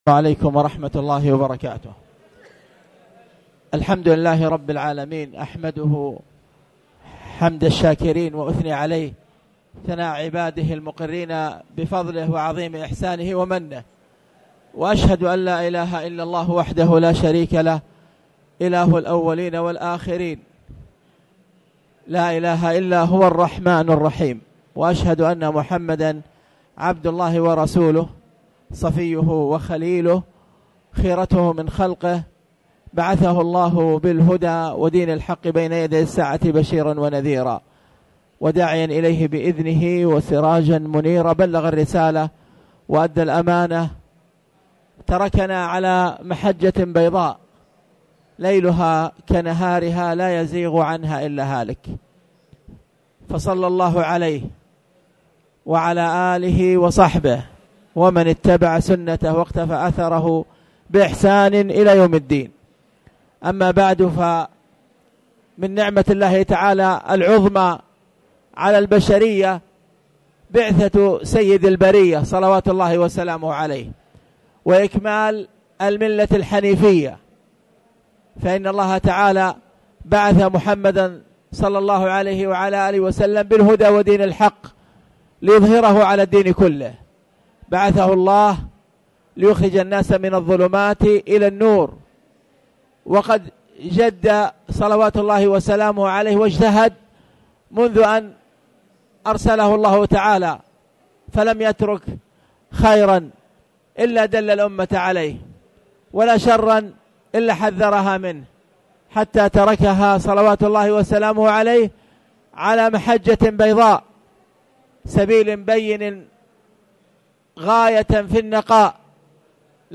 تاريخ النشر ٧ ذو الحجة ١٤٣٨ هـ المكان: المسجد الحرام الشيخ